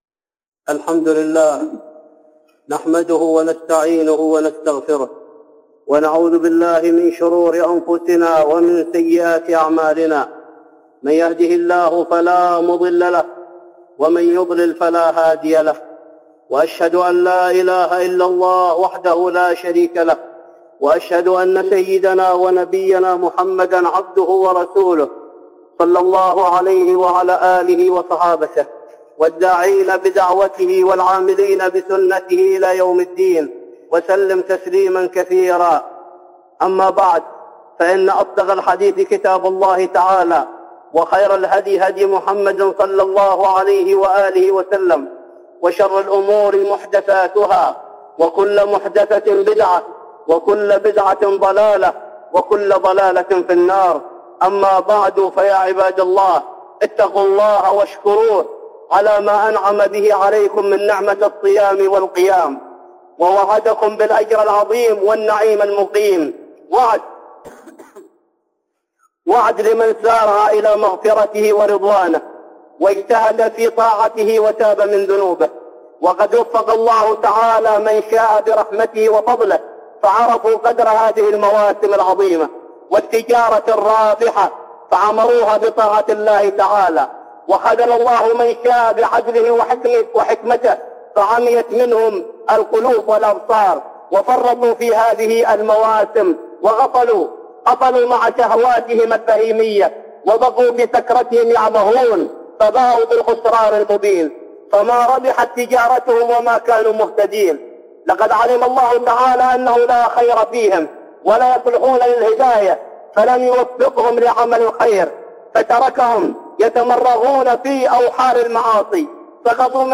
خطبة جمعة بعنوان